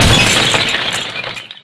Ice8.ogg